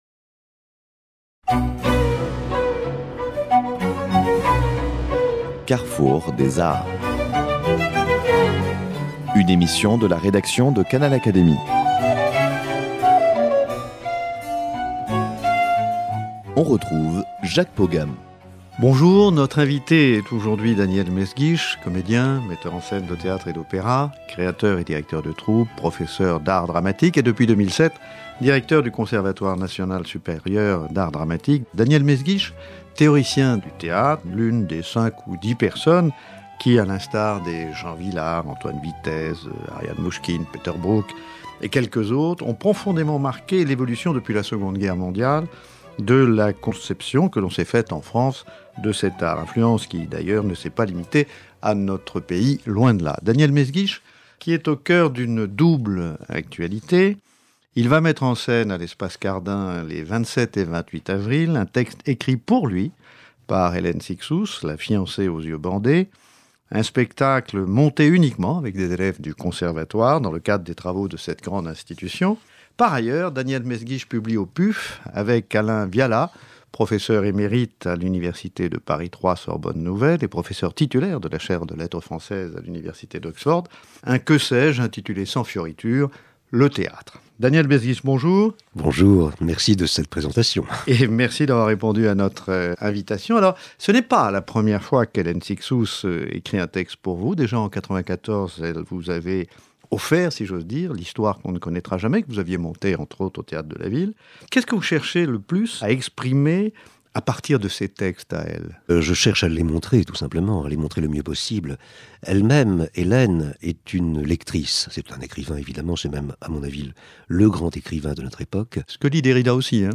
il est dans cette émission l’invité